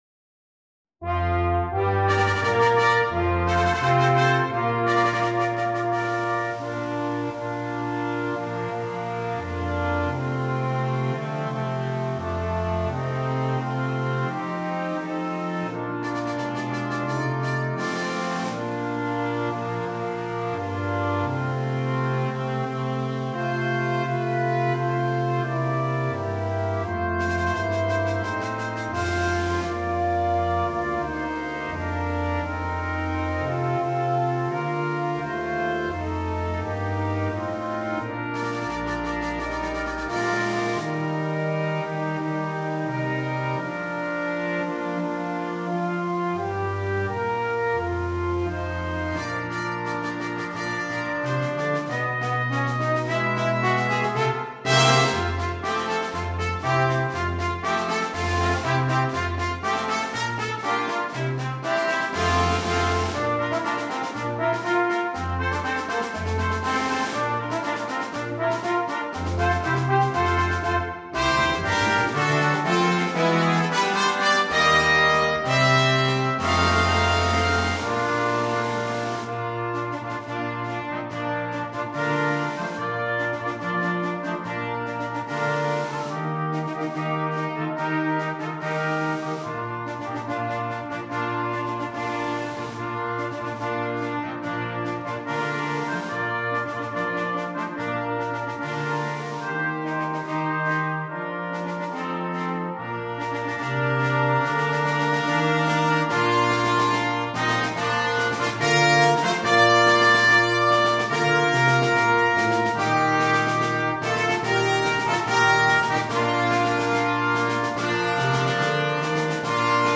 Brass Quintet arrangement
patriotic journey
medley